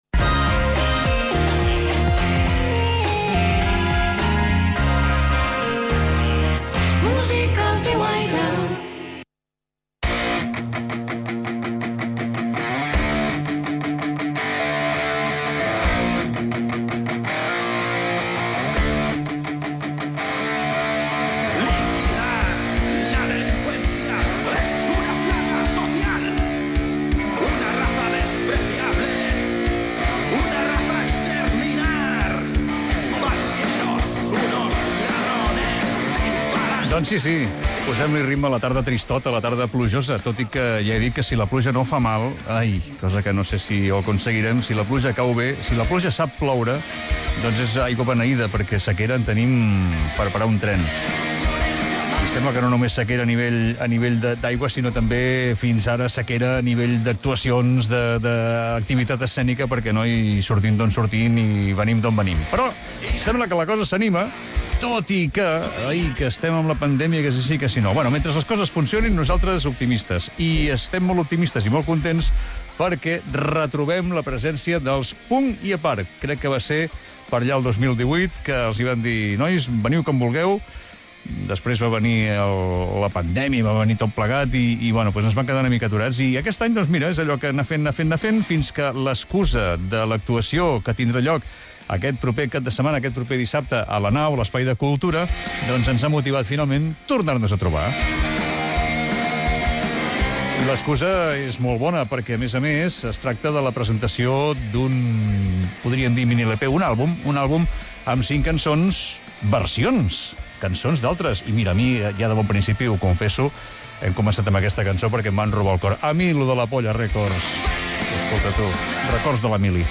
Indicatiu del programa, comentari sobre la pluja, entrevista als integrants del grup musical Puit i apart
Entreteniment
FM